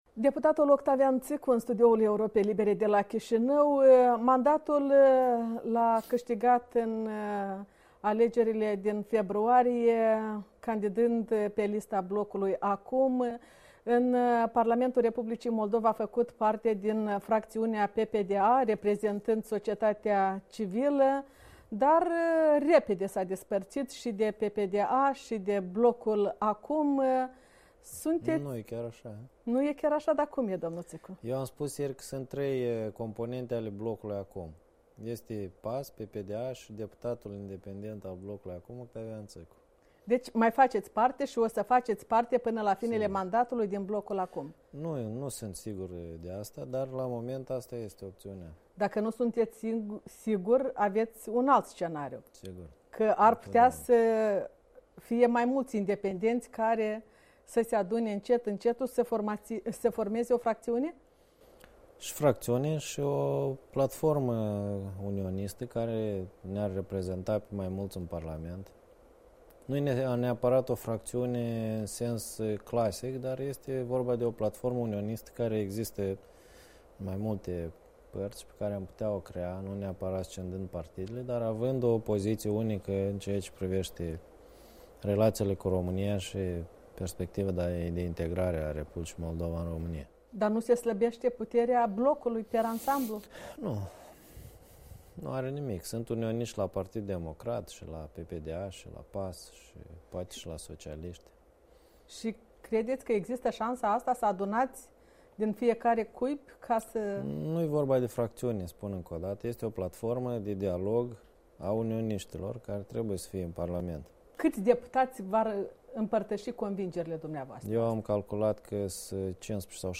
Interviu cu Octavian Țîcu